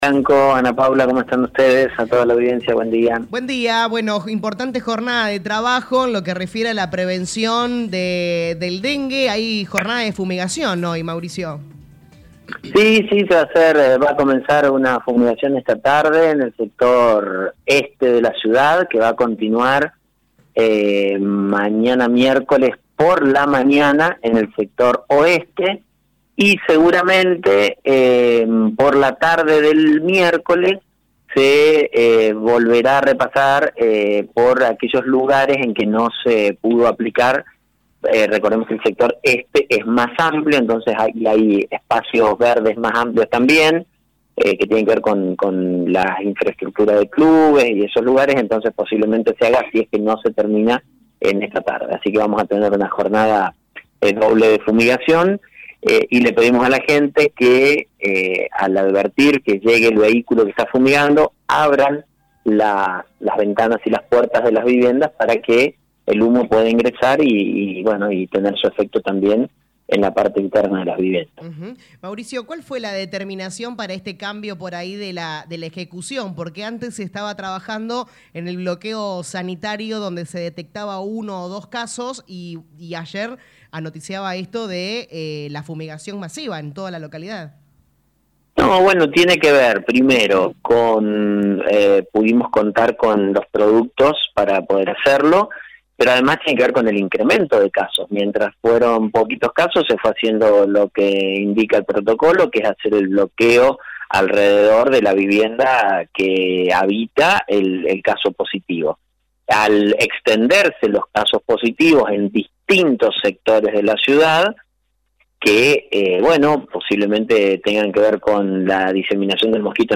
El intendente Municipal Lic. Mauricio Actis en diálogo con LA RADIO 102.9 FM habló sobre la problemática del dengue.